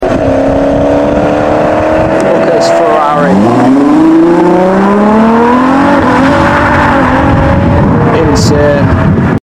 Ferrari 812 Superfast Accelarating!! 🚀🎶